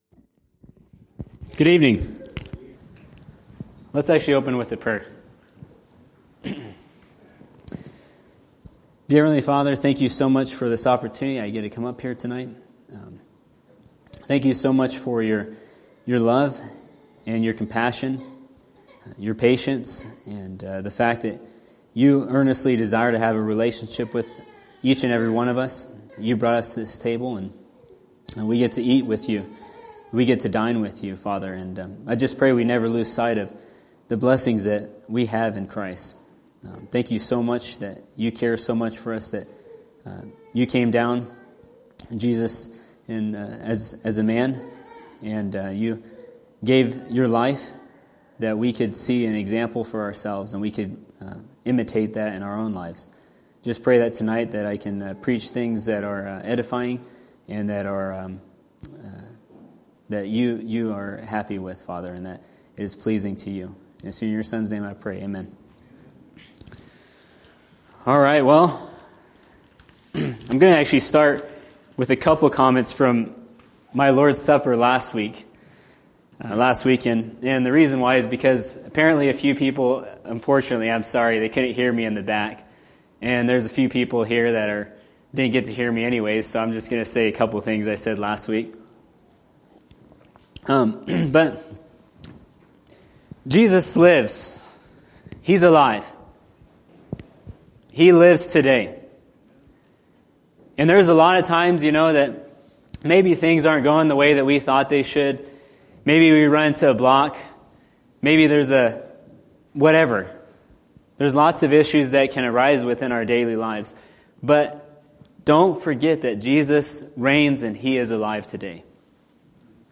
Series: Evening Messages Tagged with influence , righteousness , politically correct , tax collectors , compassion , flesh , Spirit , zeal , worship , example , Samaritan , goals , success , truth